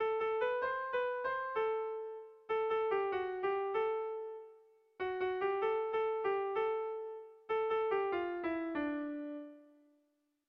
Lauko txikia (hg) / Bi puntuko txikia (ip)
AB